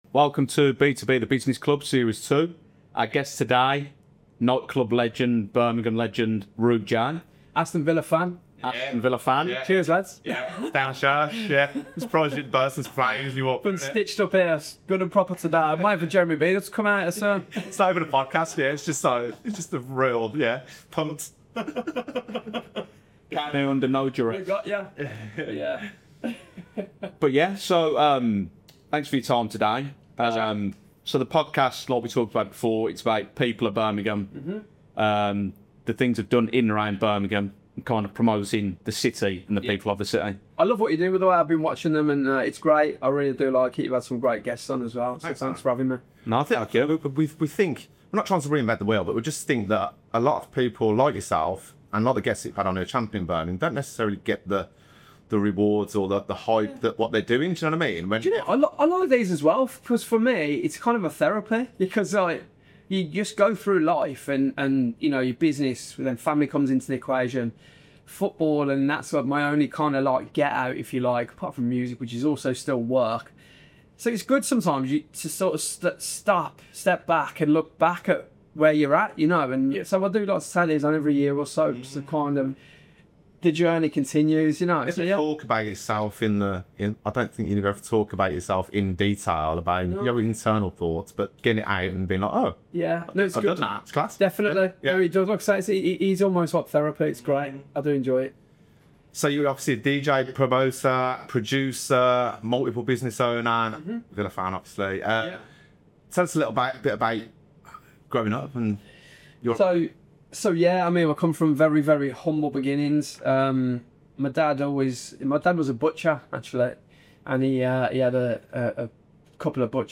Plenty of laughs, Brummie energy, and real talk about life behind the decks — and behind the business.